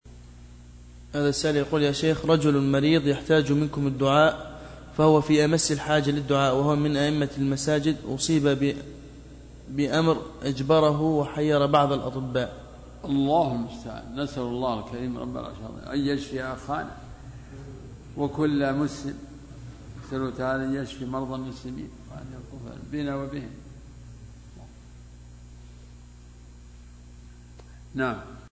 دعاء الشيخ لمرضى المسلمين